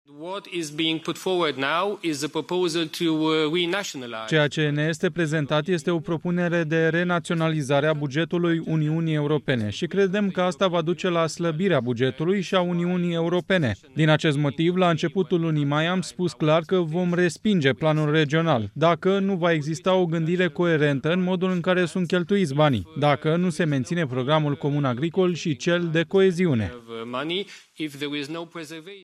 Siegfried Mureșan, europarlamentar român: Am spus clar că vom respinge planul regional dacă nu se menține programul comun agricol și cel de coeziune
16iul-19-Muresan-tradus-nu-suntem-de-acord-cu-planurile-fragmentare.mp3